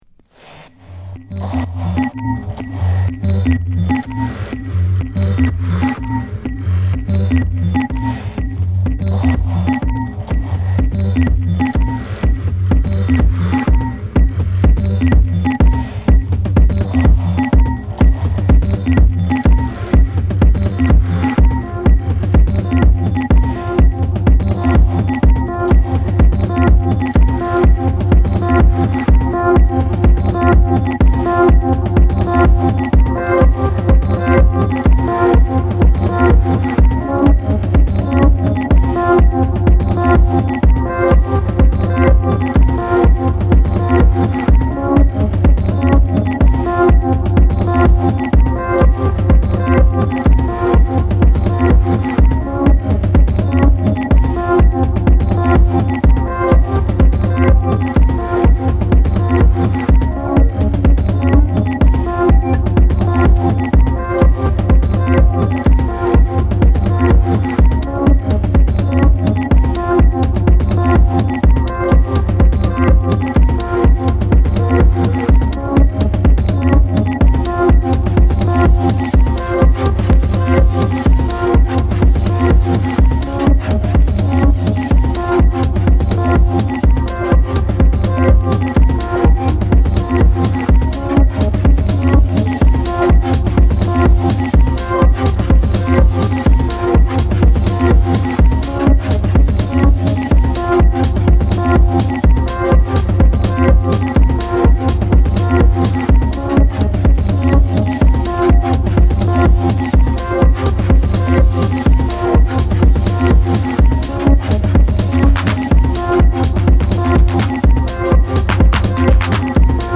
重厚感がありつつも美しく繊細に展開していく